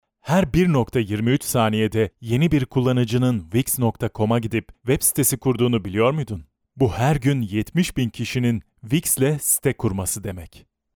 大气稳重 -广告